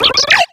Cri de Pachirisu dans Pokémon X et Y.